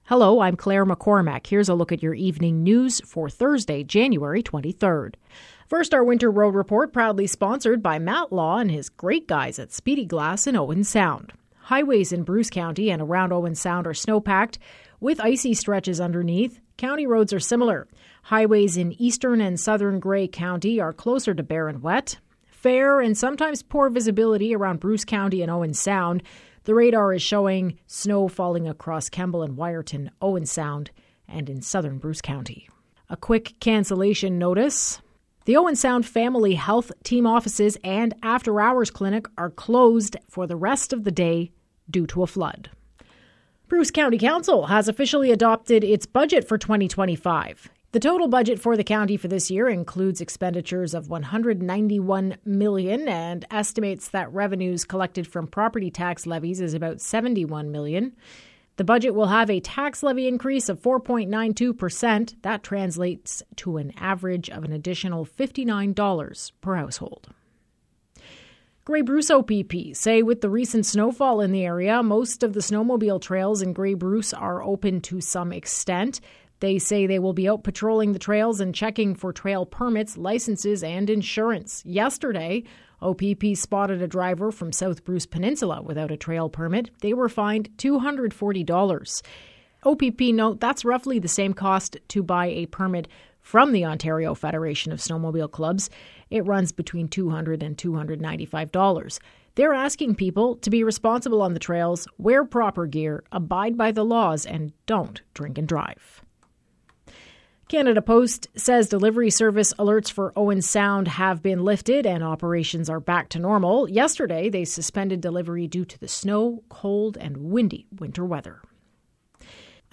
Evening News – Thursday, January 23